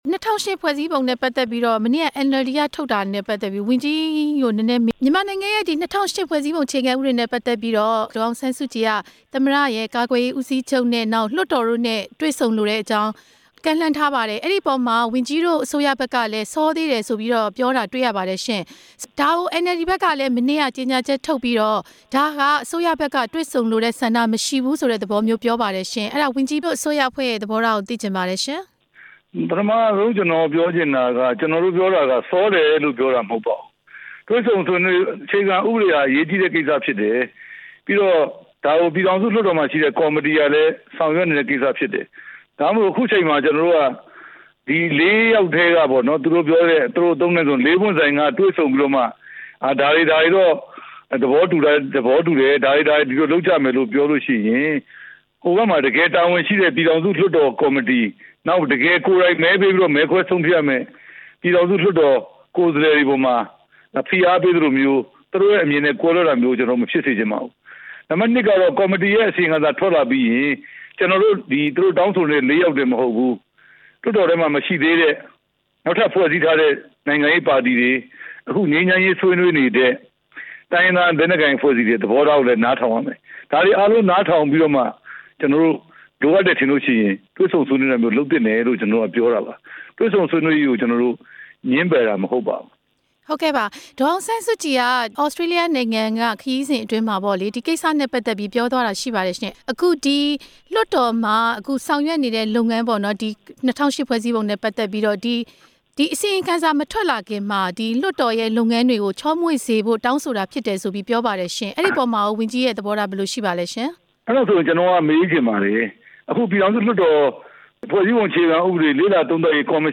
ပြန်ကြားရေးဒုဝန်ကြီး ဦးရဲထွဋ်နဲ့ မေးမြန်းချက် နားထောင်ရန်